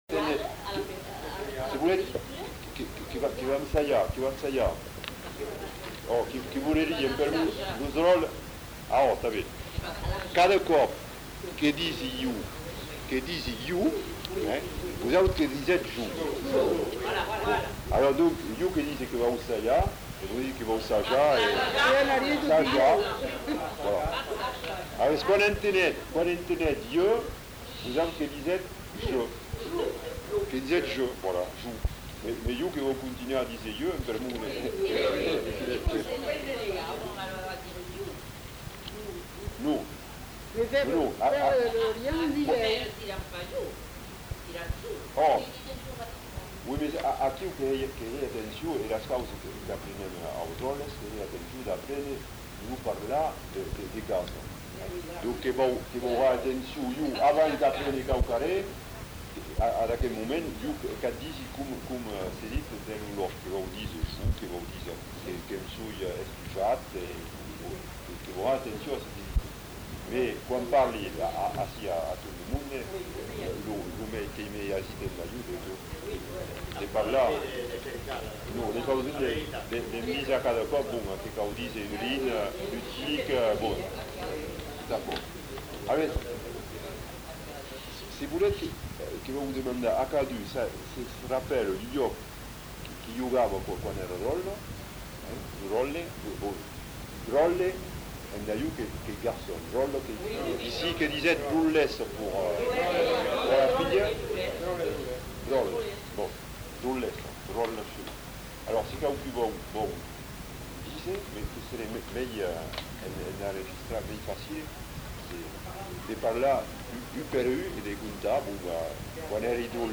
Lieu : Bazas
Genre : témoignage thématique
[enquêtes sonores]